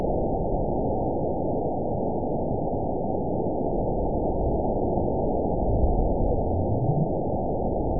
event 912535 date 03/28/22 time 23:55:59 GMT (3 years, 1 month ago) score 9.51 location TSS-AB04 detected by nrw target species NRW annotations +NRW Spectrogram: Frequency (kHz) vs. Time (s) audio not available .wav